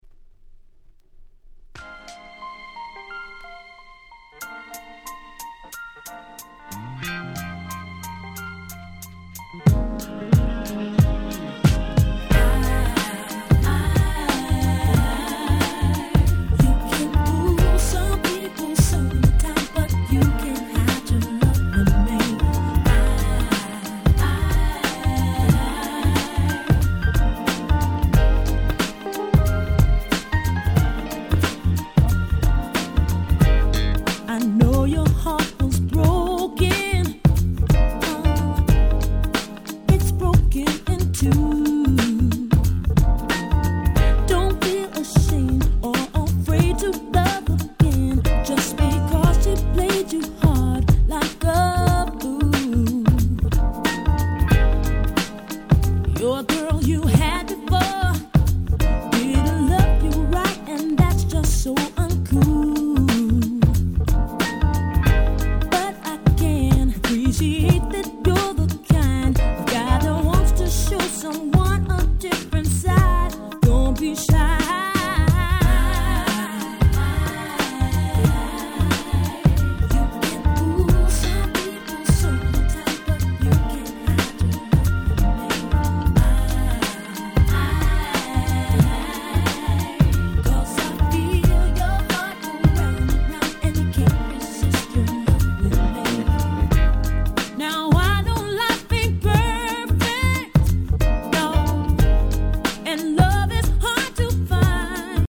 【Media】Vinyl 12'' Single
00' Nice R&B !!